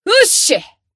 贡献 ） 分类:蔚蓝档案语音 协议:Copyright 您不可以覆盖此文件。
BA_V_Neru_Bunny_Battle_Tacticalaction_1.ogg